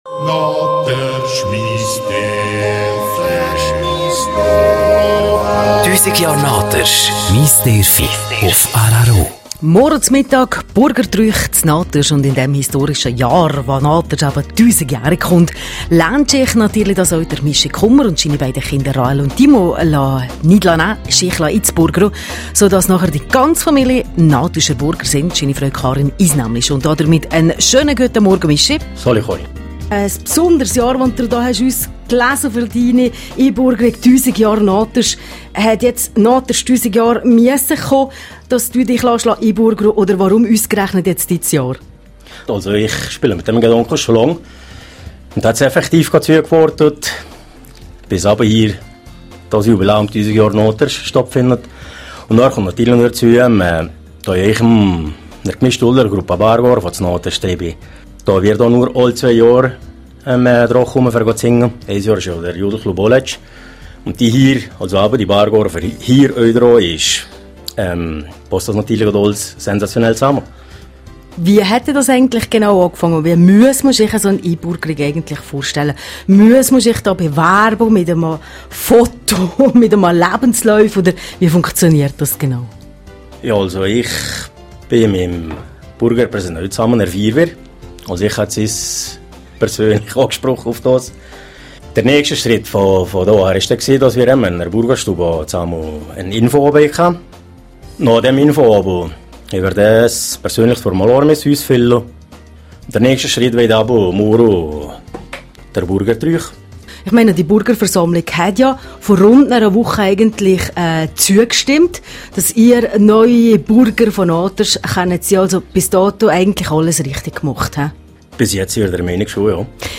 Burgertrüüch in Naters: Interview Teil 2